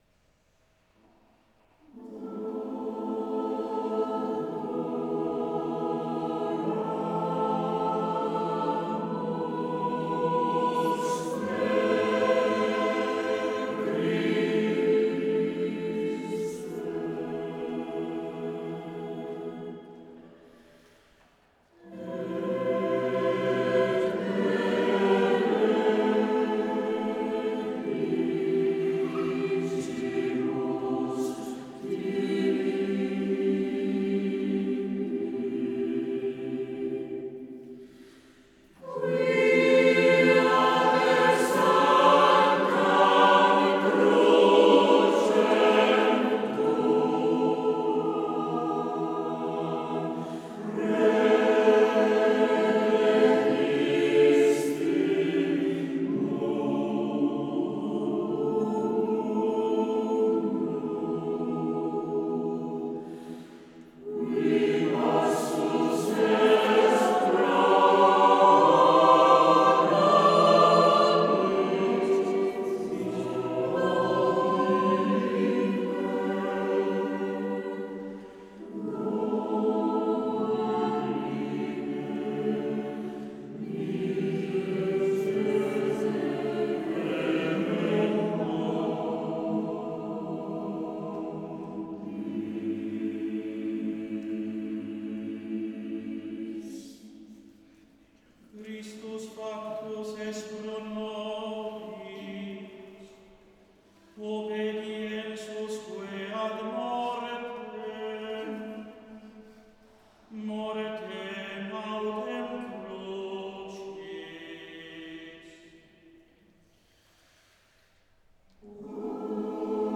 Elevazione Musicale Santa Pasqua 2025.
Basilica di S.Alessandro in Colonna, Bergamo
4 voci miste